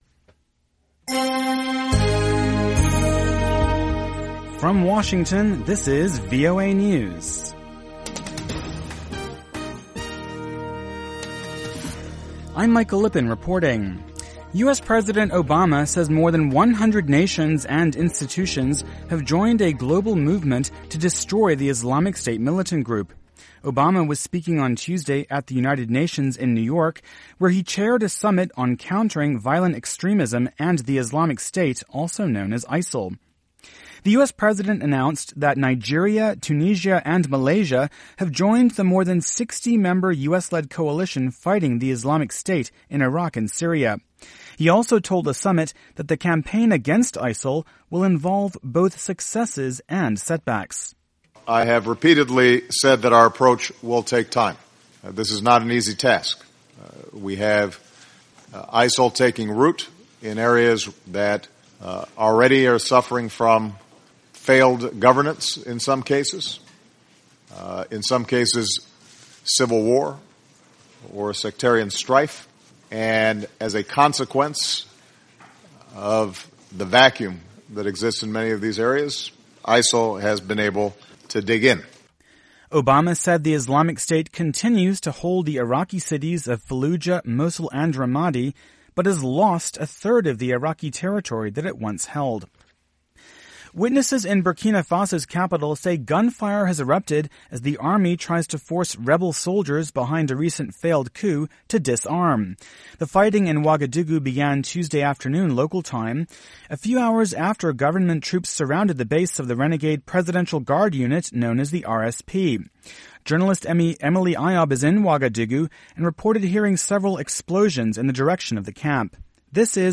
VOA English Newscast - 2000 UTC September 29, 2015